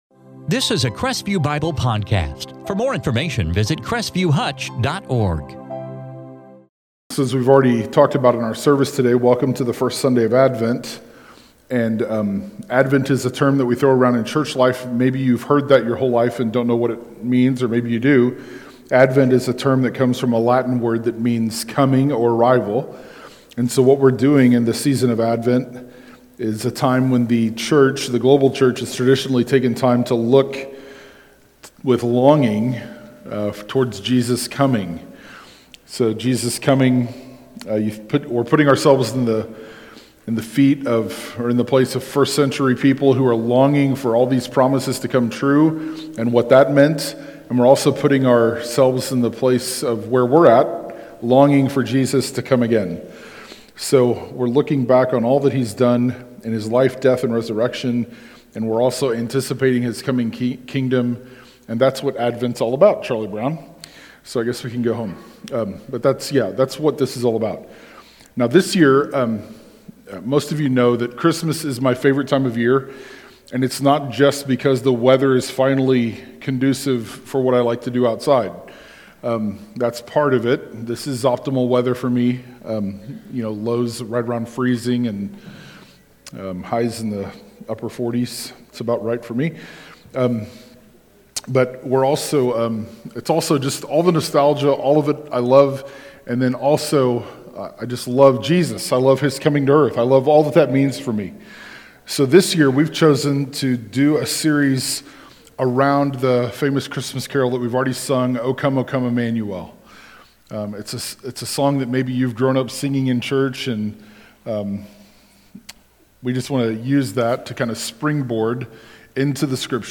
Topic Advent